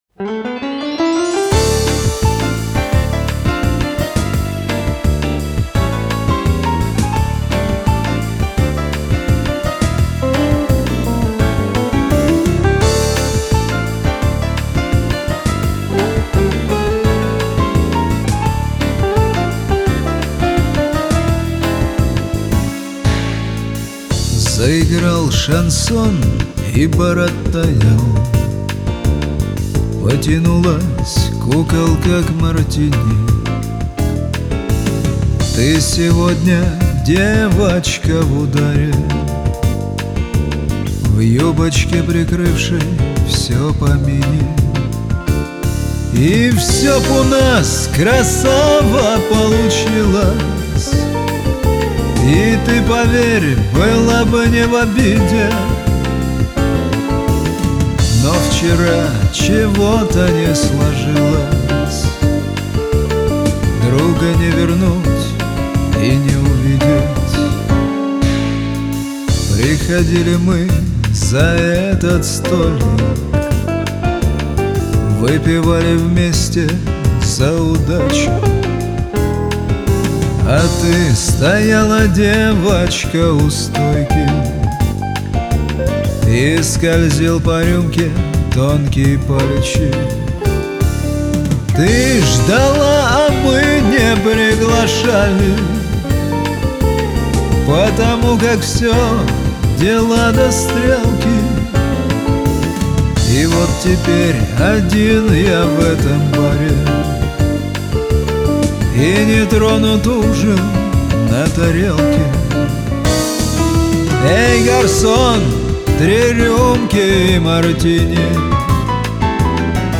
А лирично!!! Хотя и грустно!!!